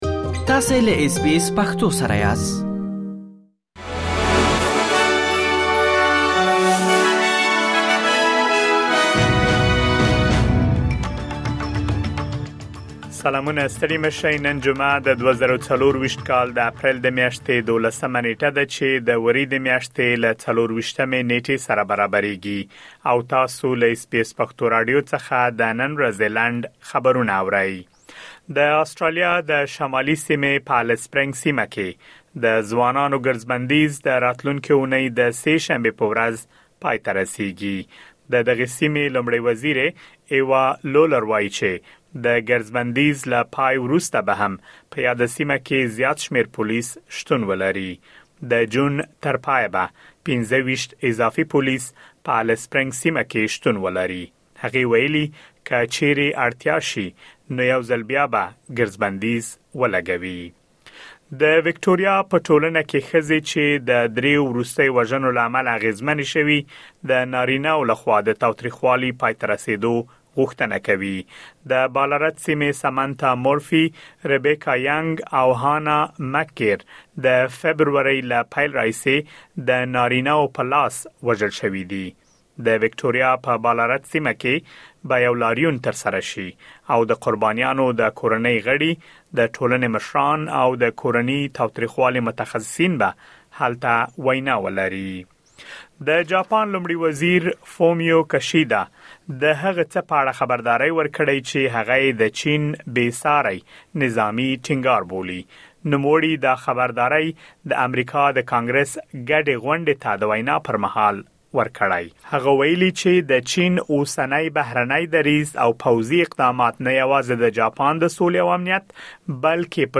د اس بي اس پښتو راډیو د نن ورځې لنډ خبرونه|۱۲ اپریل ۲۰۲۴
اس بي اس پښتو راډیو د نن ورځې لنډ خبرونه دلته واورئ.